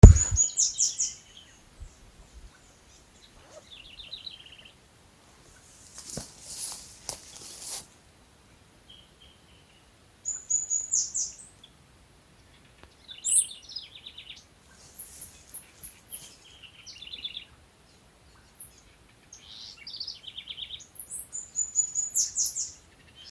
Arremon flavirostris polionotus
Nome Português: Tico-tico-de-bico-amarelo
Localidade ou área protegida: Parque Nacional Mburucuyá
Condição: Selvagem
Certeza: Gravado Vocal